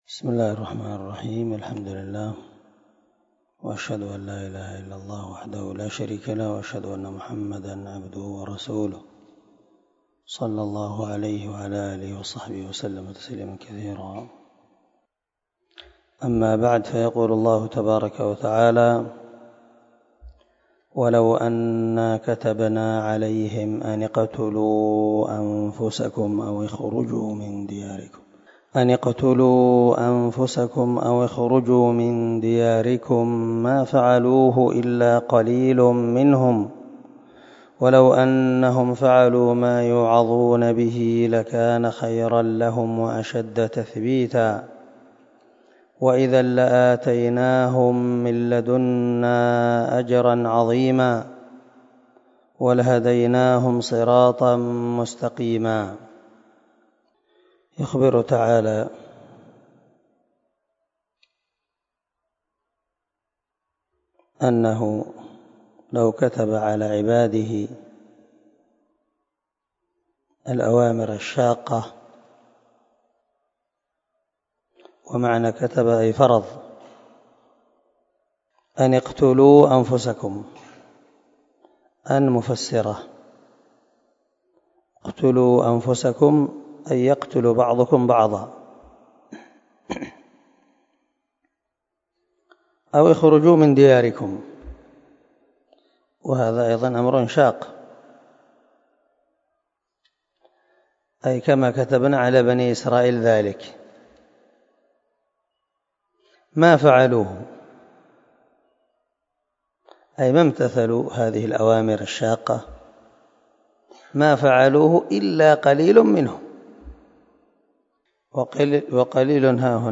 277الدرس 45 تفسير آية ( 66 – 68 ) من سورة النساء من تفسير القران الكريم مع قراءة لتفسير السعدي